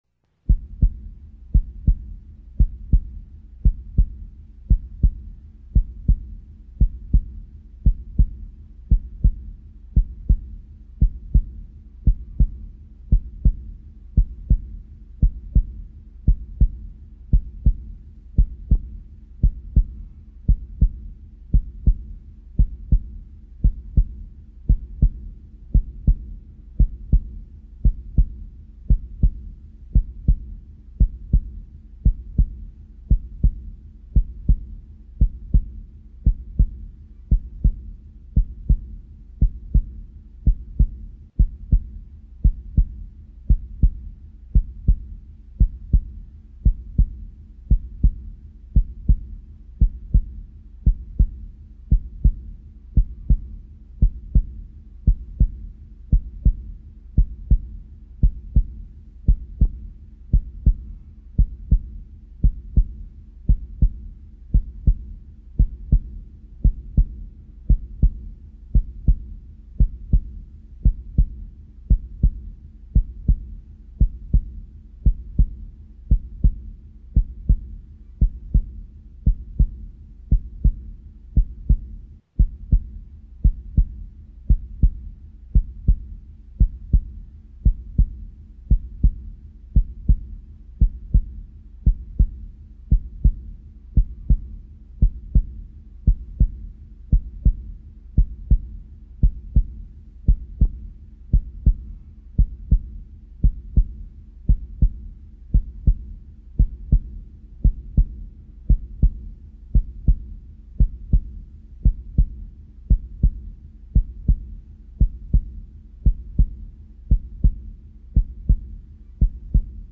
stuk_serdca.mp3